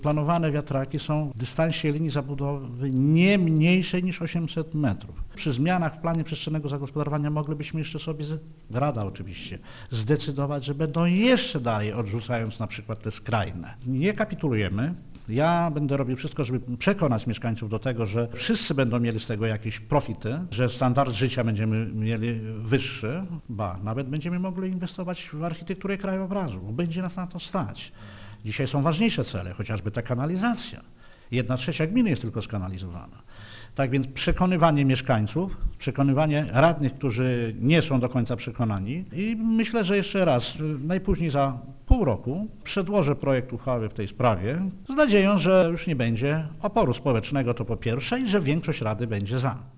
Dlatego wójt Marzęda zapowiada, że będzie wracać do tego pomysłu i podkreśla, że: